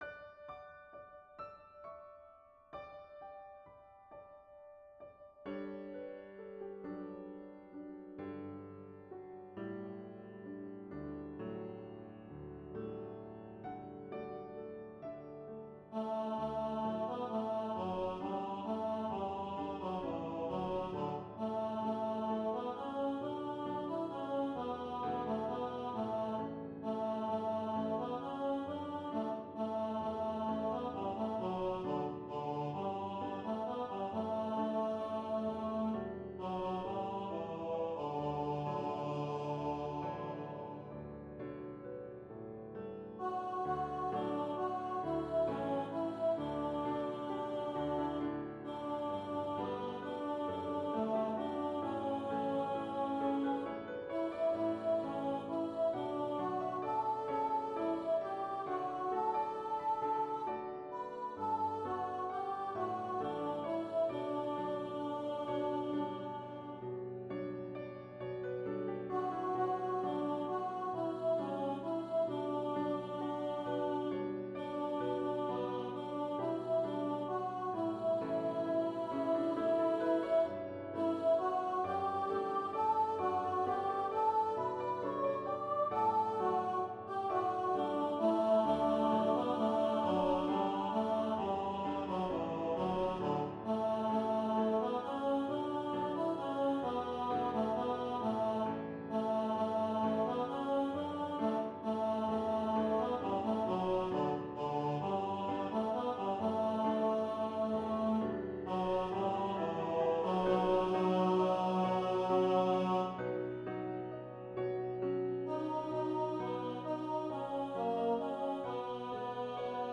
Vocal Duet and Piano
Hymn arrangement